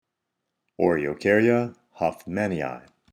Pronunciation/Pronunciación:
O-re-o-cár-ya  hoff-mánn-i-i
Oreocarya_hoffmannii.mp3